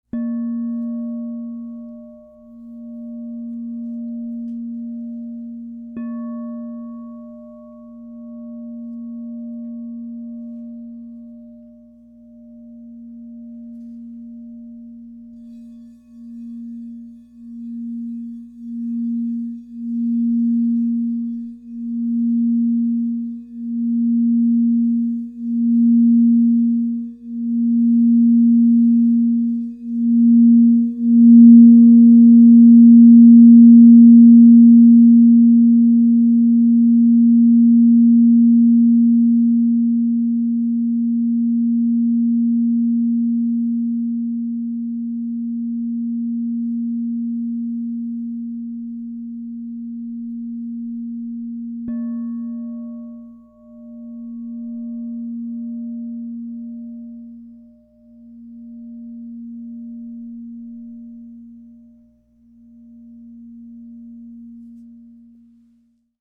Genuine Crystal Tones® Alchemy Singing Bowl.
Grandfather Frosted Inside 10″ A# +5 Crystal Tones Singing Bowl
SKU: 156345 Out of stock Alchemy Grandfather Brand Crystal Tones Cents +5 (True Tone) Color Orange Diameter 10" Frequency 440Hz (TrueTone), 528Hz (+) MPN 156345 Note A# SKU: 156345